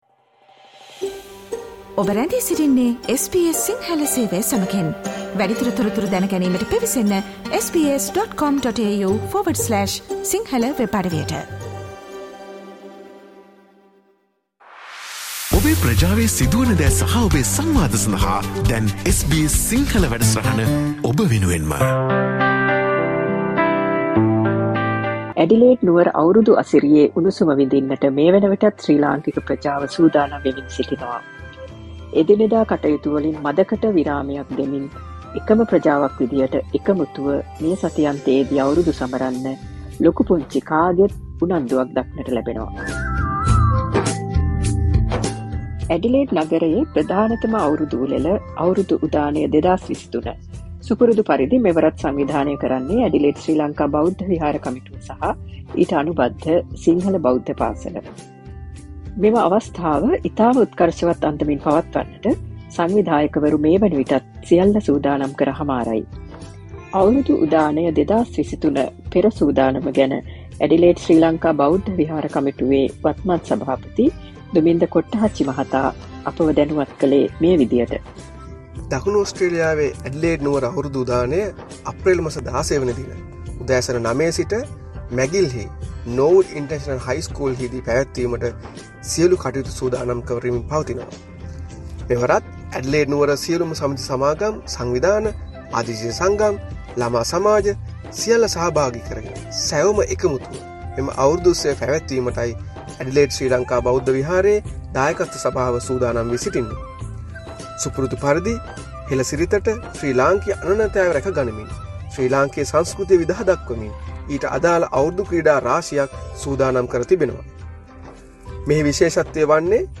Adelaide Sinhala New Year Festival_ SBS Sinhala reports